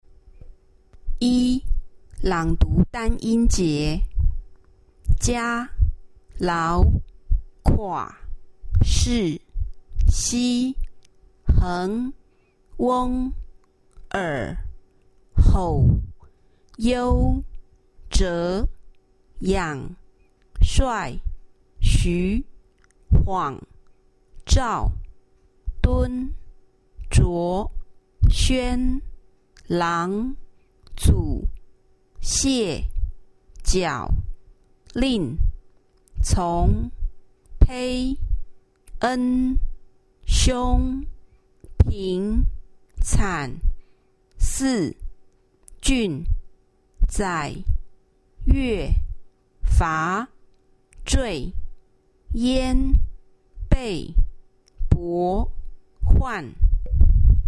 Tasks 1&2 Word Reading
Taiwan Sample: